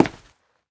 scpcb-godot/SFX/Step/Run2.ogg at master